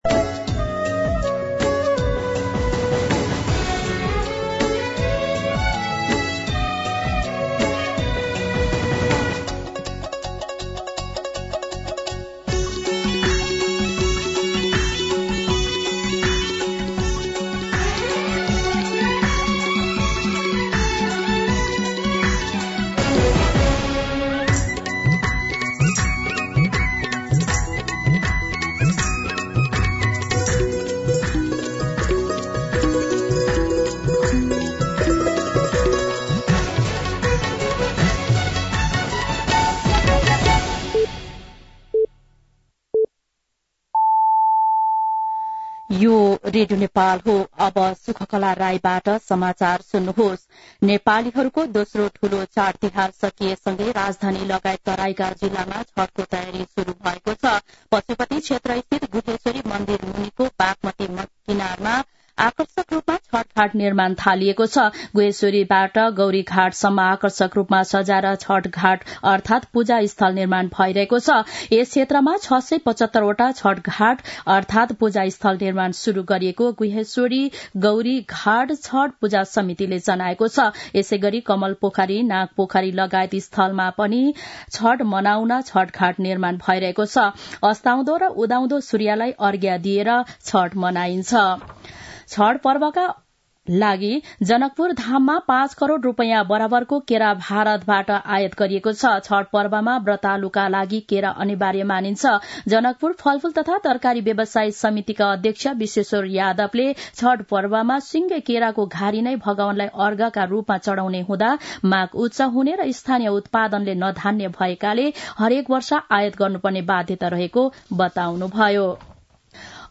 मध्यान्ह १२ बजेको नेपाली समाचार : २० कार्तिक , २०८१
12pm-News-19.mp3